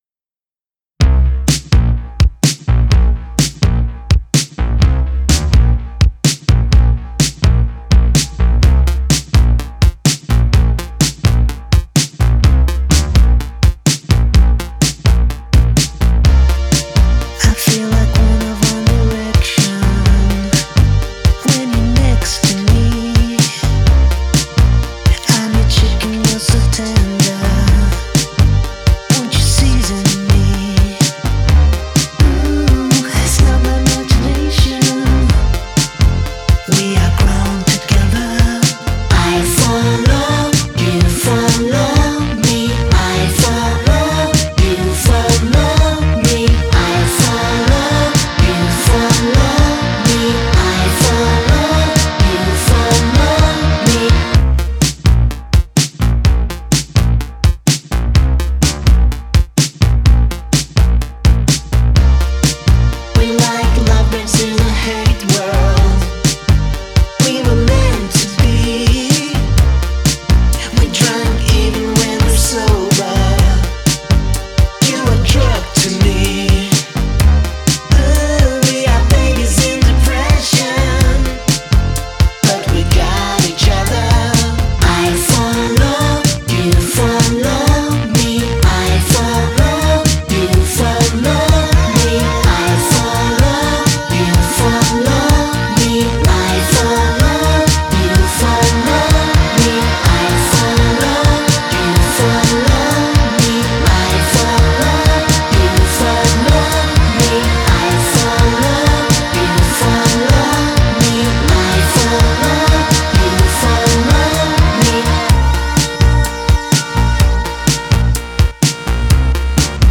Жанр: Indie Pop
some kind of French Electro-pop